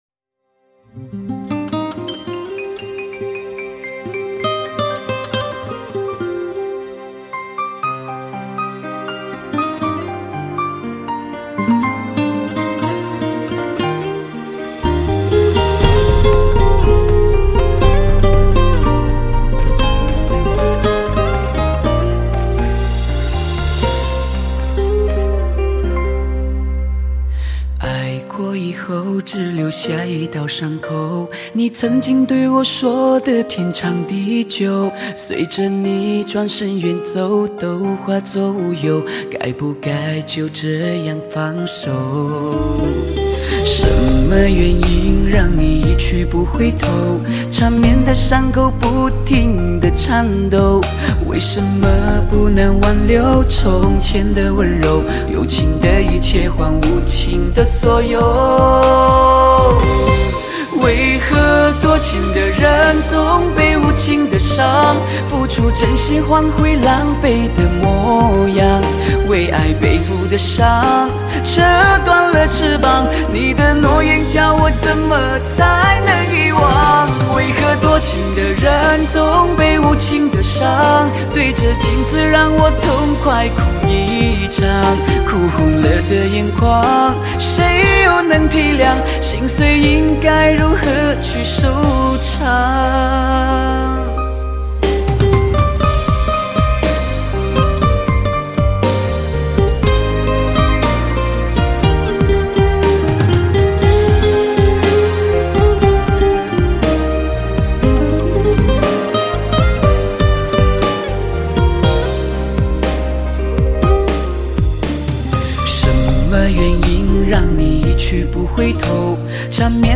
情歌可以让人身临其境
汽车一族专用唱片 高清环绕